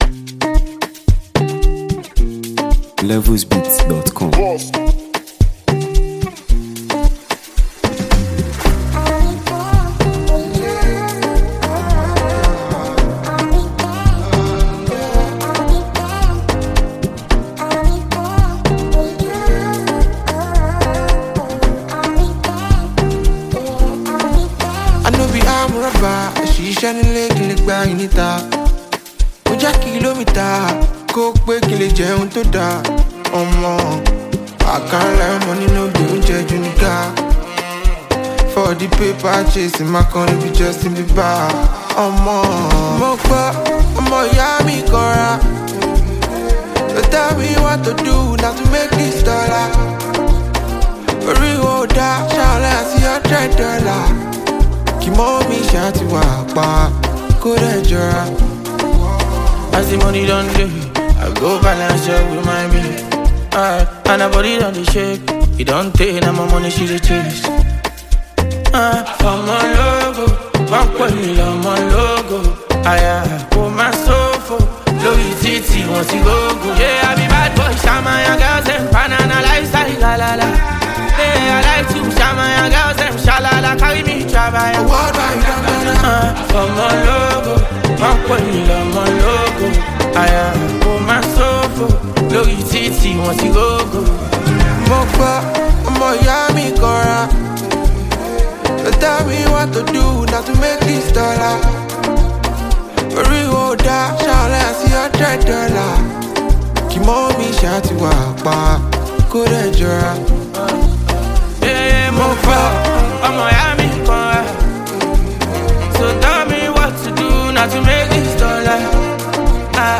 Talented Nigerian street-pop sensation and songwriter